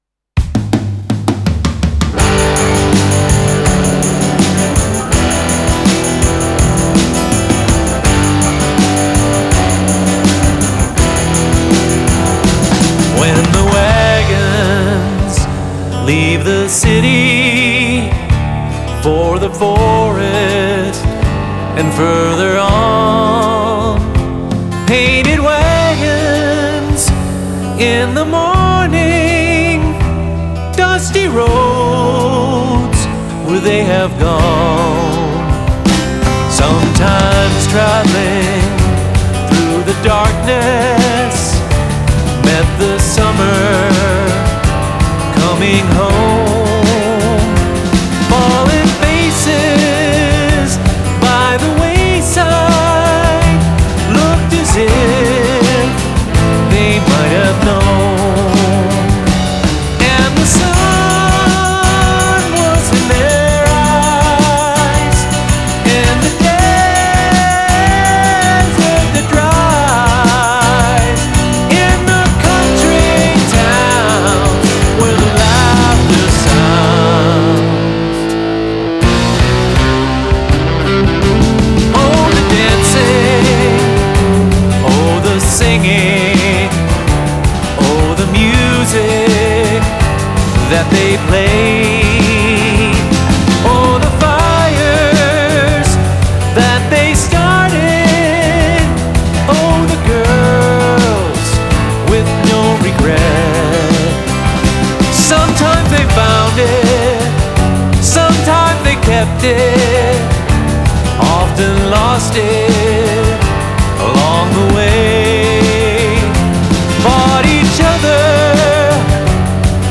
It’s all very compact and still dynamic.
It’s a great mix and sounds so authentically 70s.